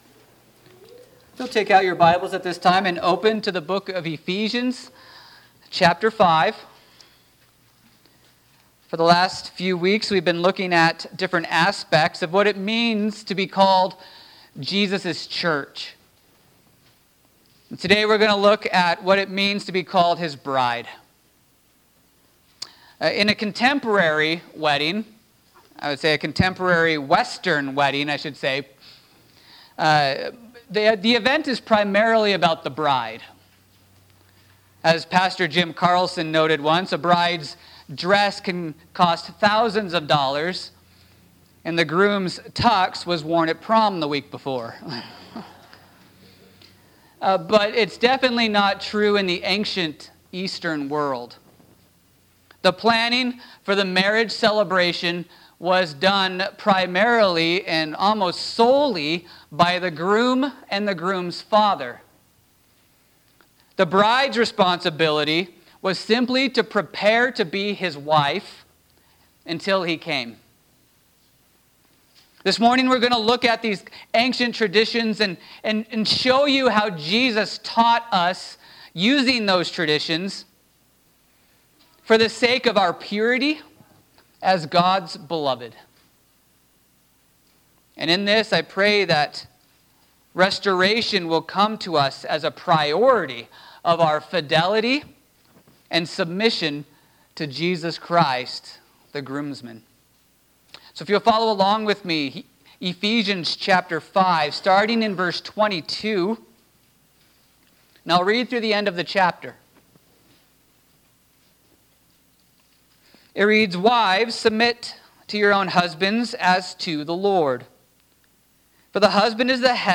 2024 Church Is…The Bride of Christ Preacher